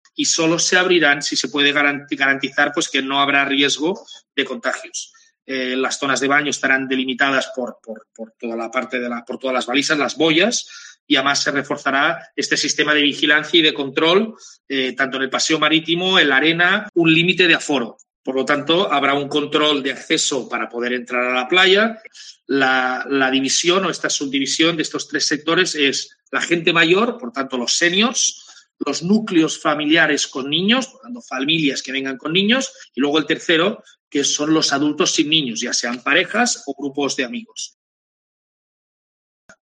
Jaume Dulset, alcalde de Lloret explica las nuevas medidas para las playas